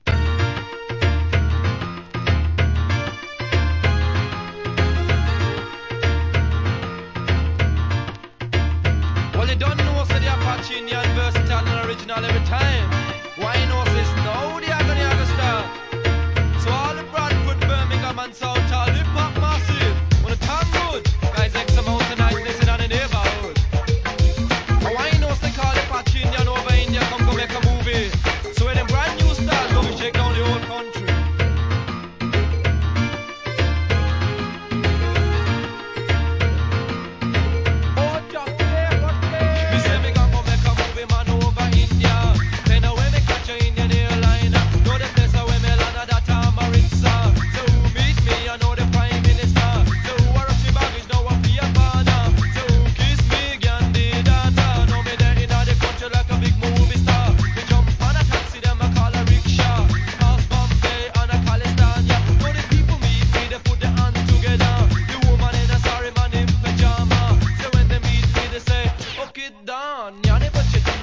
REGGAE
ラガHIP HOPなREMIX!!